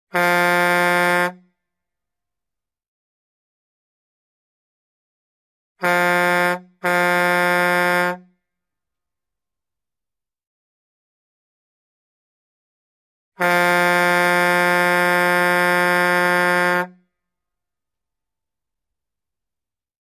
Звуки грузовиков
На этой странице собраны разнообразные звуки грузовиков: от рёва мощных двигателей до сигналов и шума шин на дороге.